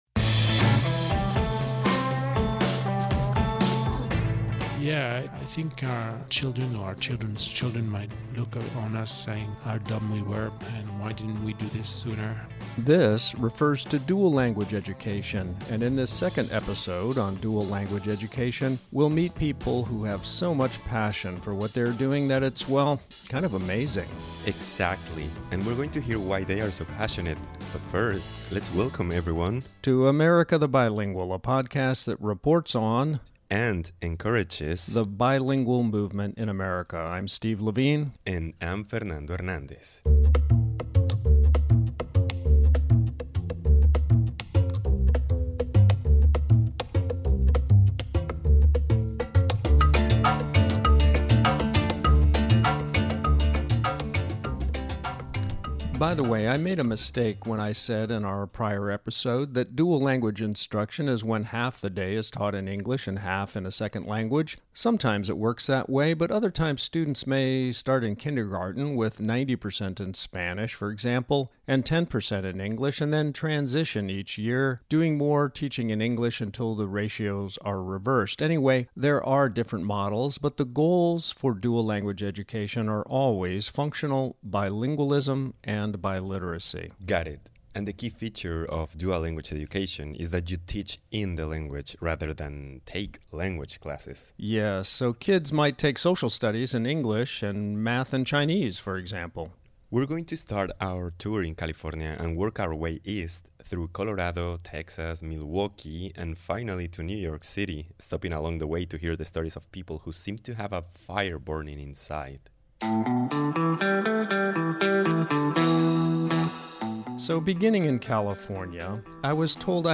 In this second episode on the dual-language movement, we hear from six voices of change in America.